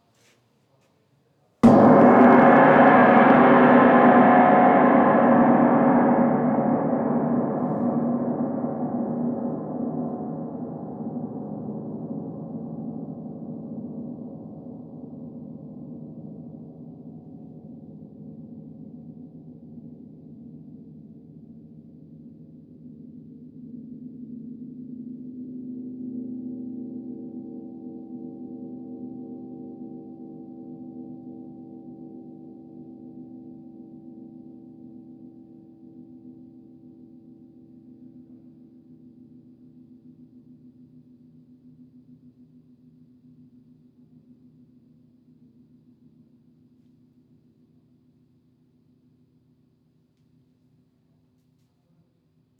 moyen.wav